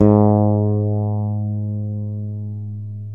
Index of /90_sSampleCDs/Roland - Rhythm Section/BS _E.Bass 3/BS _Ch.Fretless